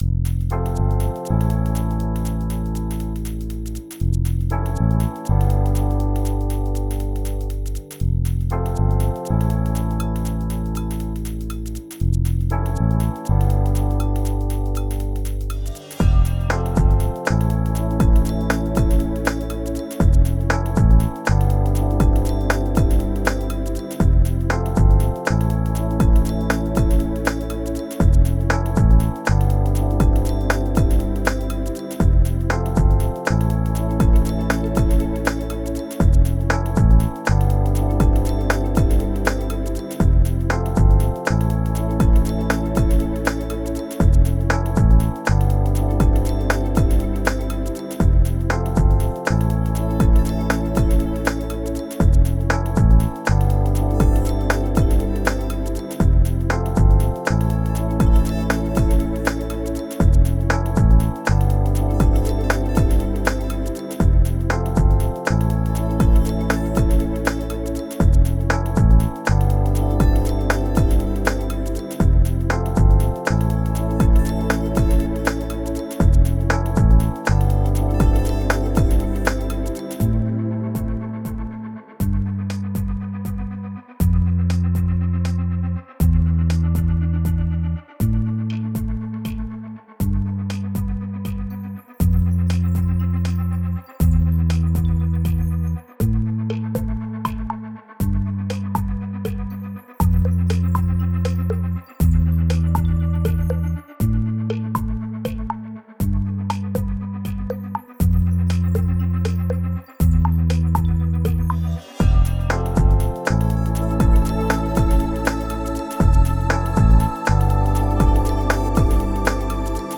Chillout track for sports and reality TV..